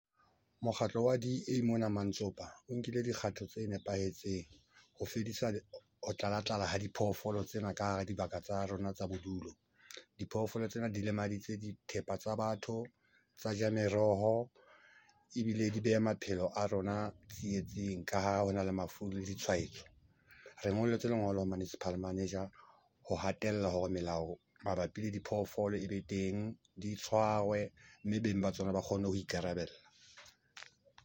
Sesotho soundbites by Cllr Leonard Masilo.